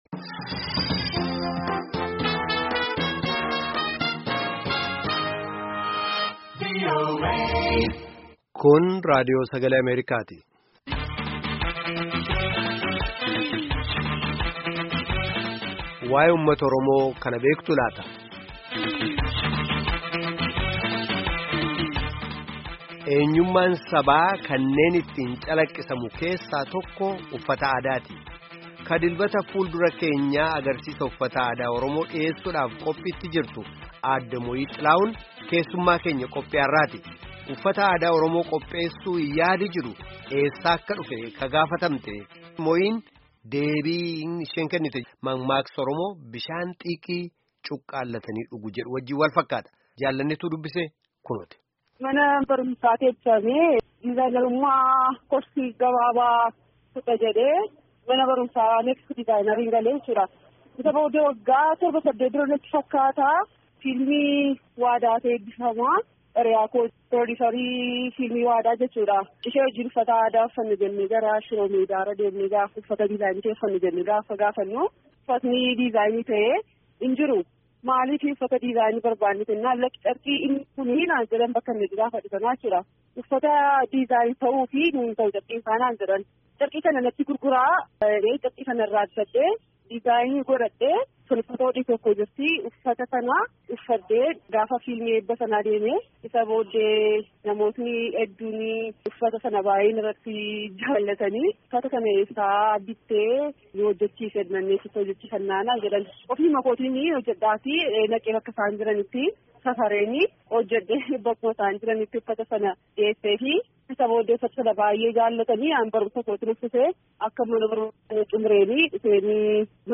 Gaaffii fi deebii gaggeeffame caqasaa.